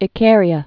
(ĭ-kârē-ə, ī-kâr-)